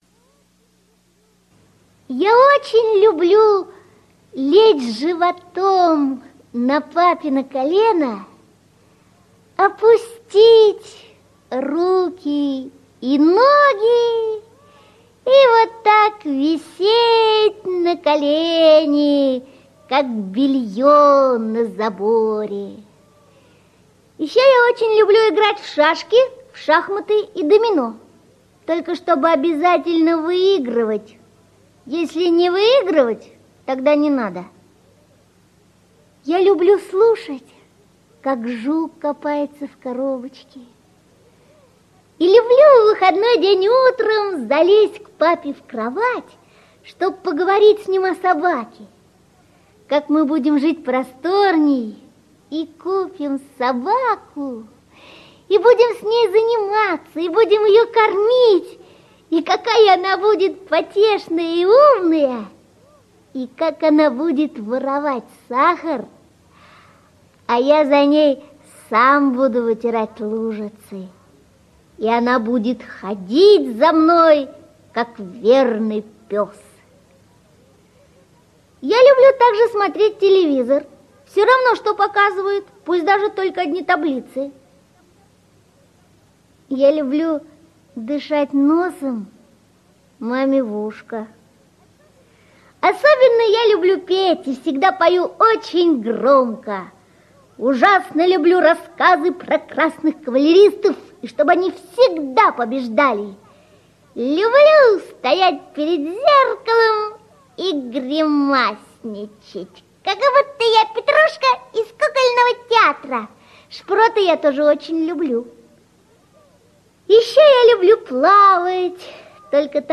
Аудиорассказ «Что я люблю»